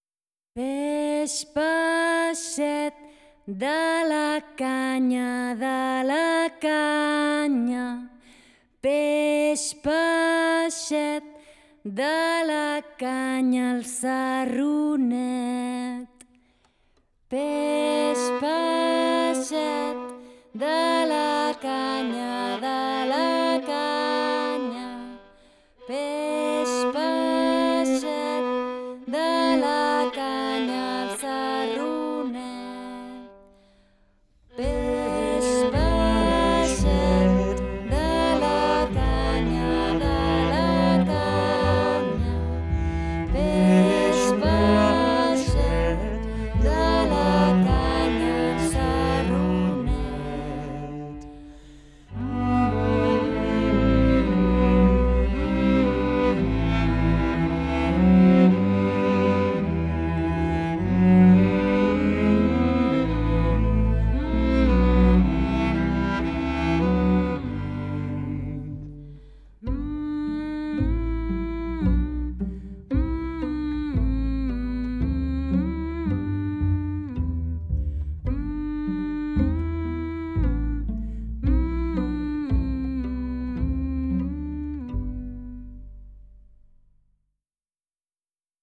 Hem anat al Palau de la Música a gaudir de Twinkle Twinkle Baby’s Star, un concert-espectacle on hem disfrutat de cançons i melodies de tradició anglesa i catalana posades al dia  amb un tractament musical modern i integrador de diferents llenguatges i estils musicals.